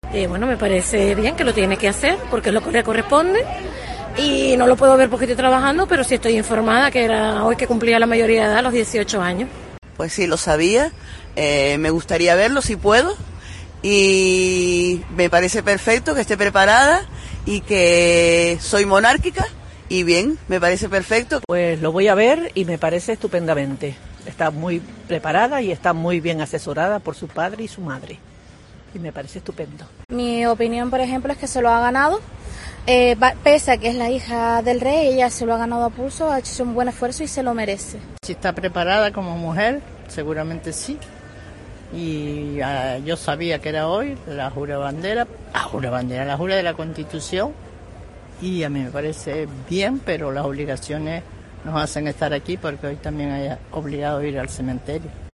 Ciudadanas canarias valoran la jura de la Constitución de la princesa Leonor